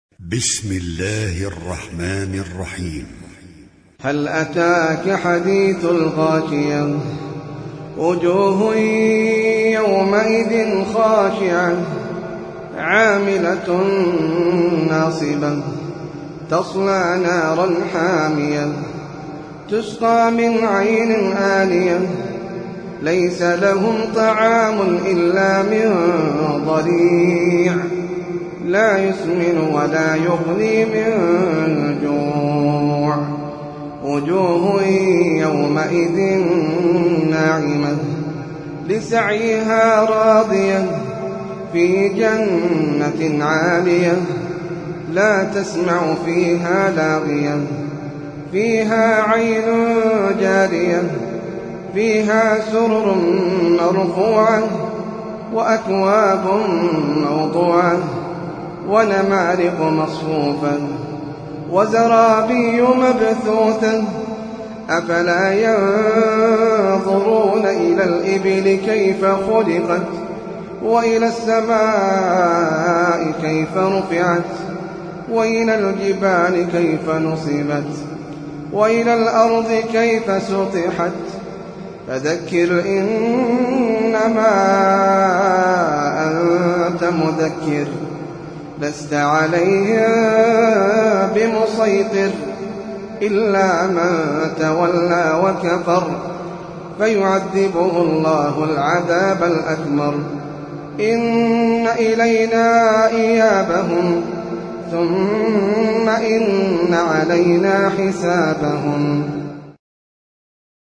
المصحف المرتل (برواية حفص عن عاصم)
جودة عالية